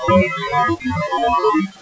Electronic Voice Phenomena
This next EVP was captured as we were preparing to leave.
Listen as this spirit clearly asks us not to turn off the recorder.